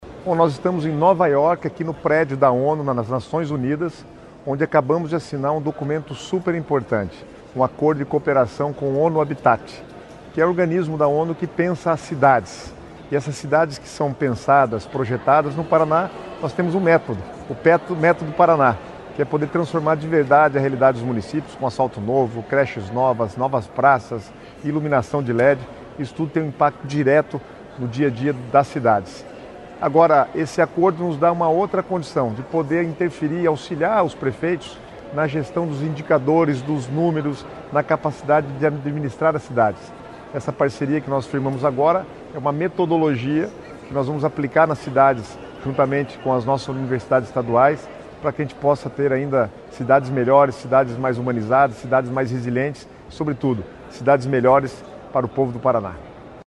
Sonora do secretário Estadual das Cidades, Guto Silva, sobre a parceria assinada com a ONU-Habitat, em Nova York